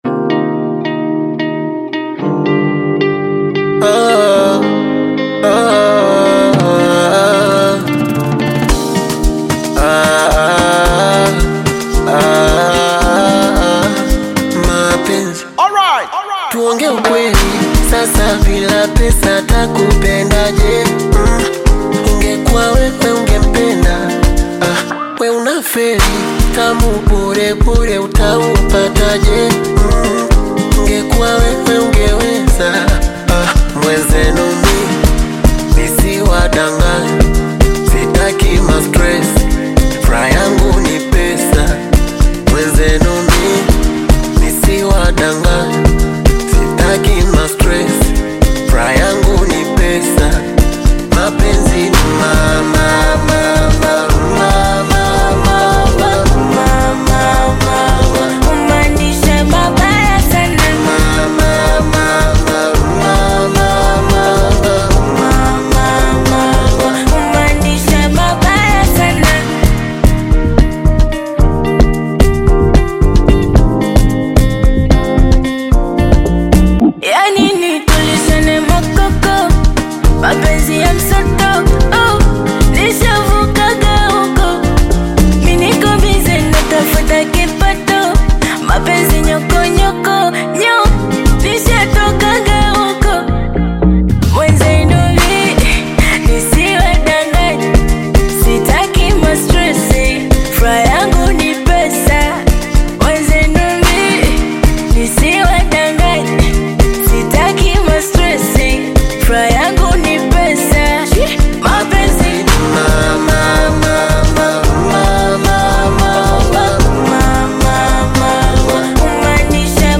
Tanzanian Bongo Flava
is a love song that falls within the Bongopiano genre